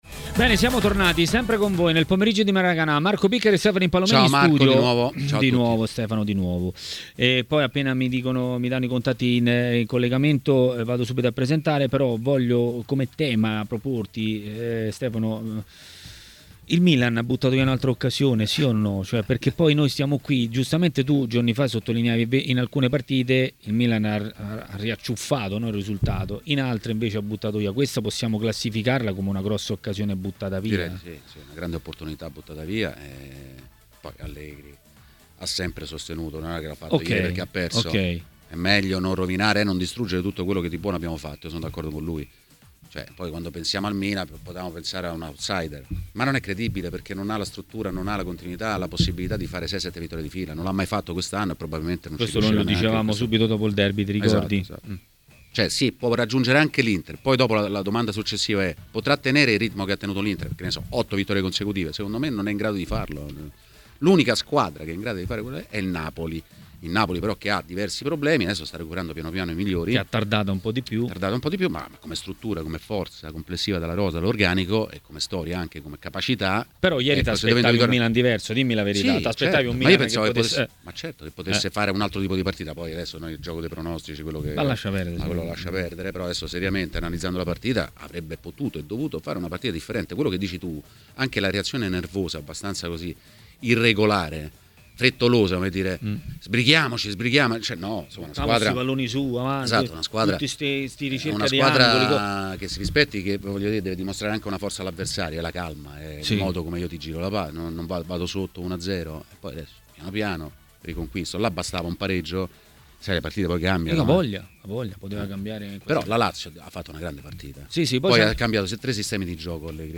è intervenuto a TMW Radio, durante Maracanà, per parlare dei temi del giorno.